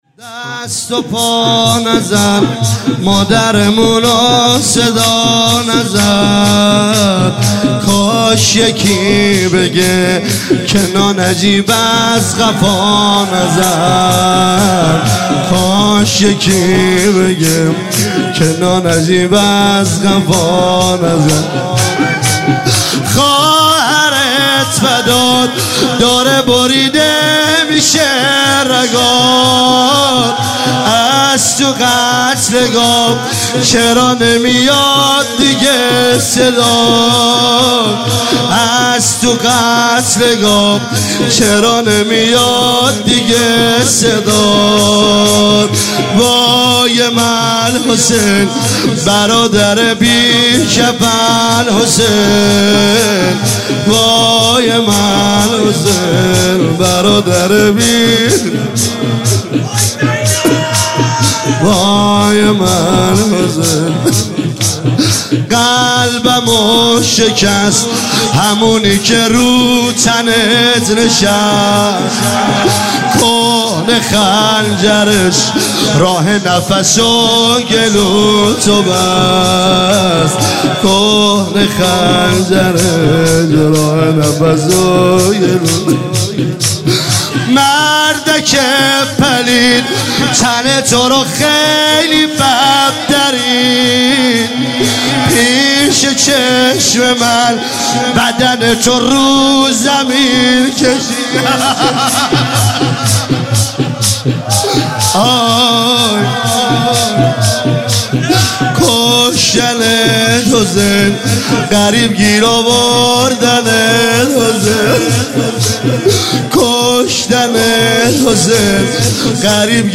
عنوان شب ششم ماه مبارک رمضان ۱۳۹۸
مداح
شور